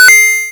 Typical interface complete ding Share on Discord, TikTok & WhatsApp.
06310 completed ding 3